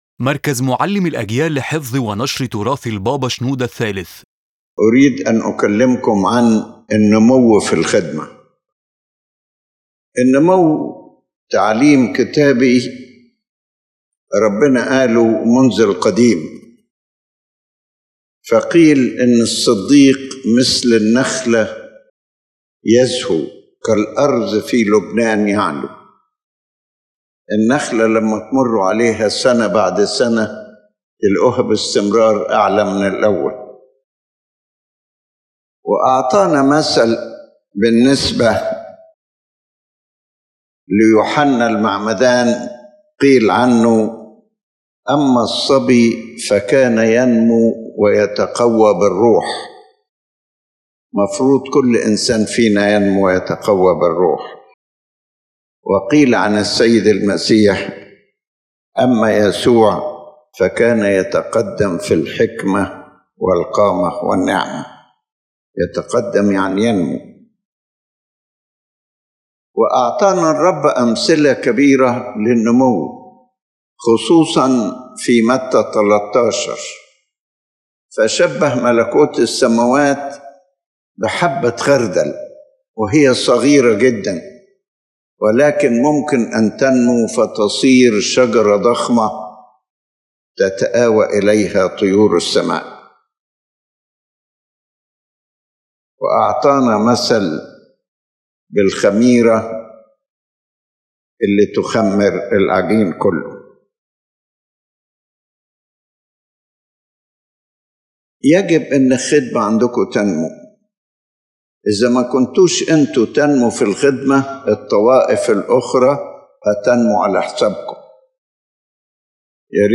The Fifth Conference at St. George Church, Heliopolis – Growth in Ministry